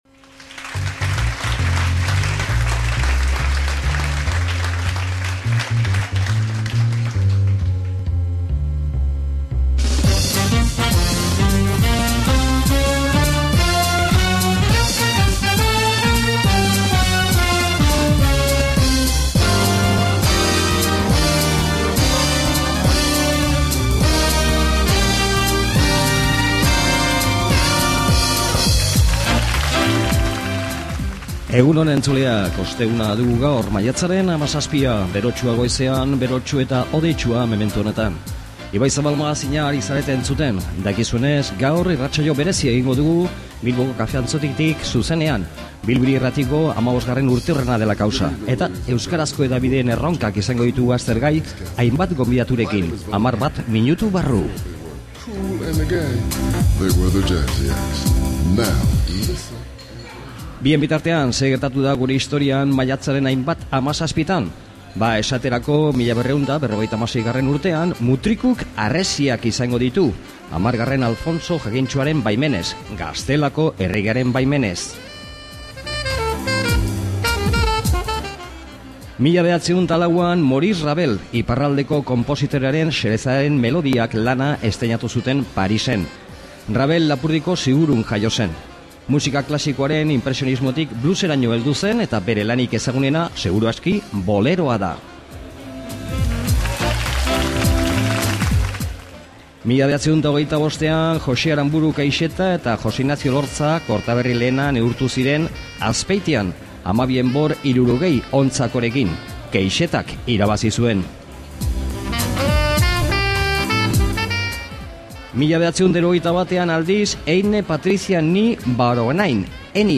SOLASALDIA
Irrati honen 15. urteurrena dela kausa, gaur magazine berezia egin dugu, zuzenean Bilboko Kafe Antzokitik.